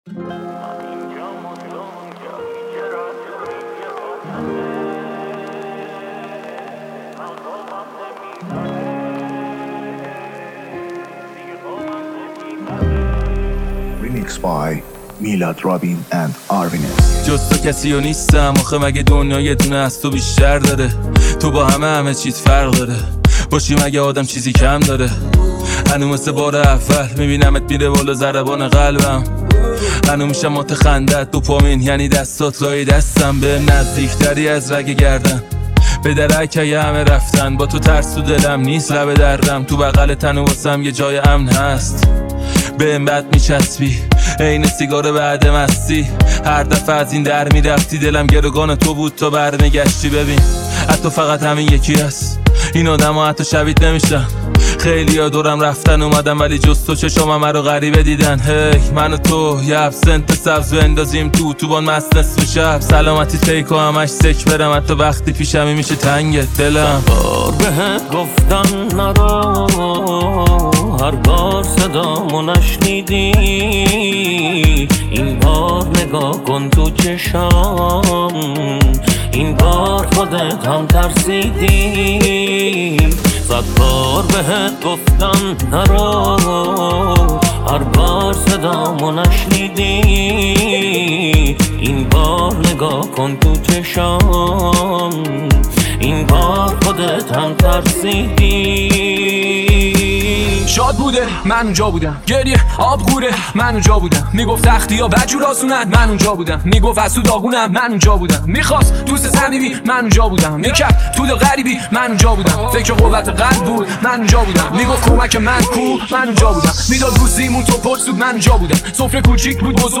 دانلود ریمیکس جدید رپ